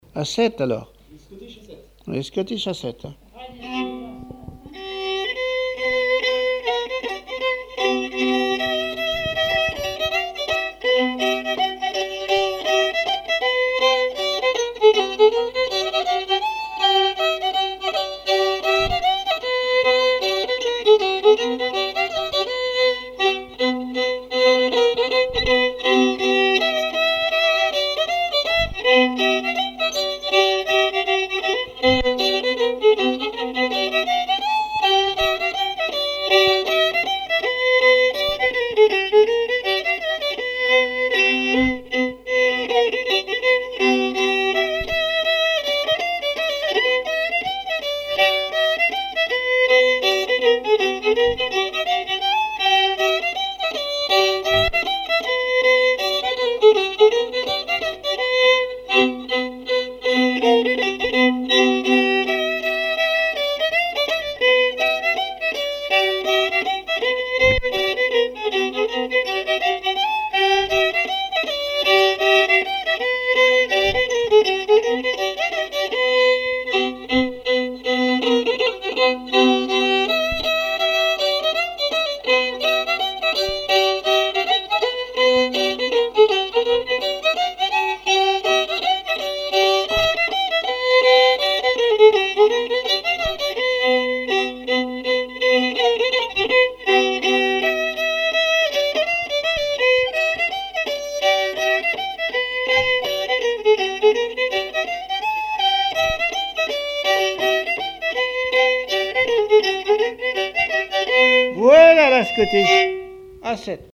danse : scottich sept pas
répertoire musical au violon
Pièce musicale inédite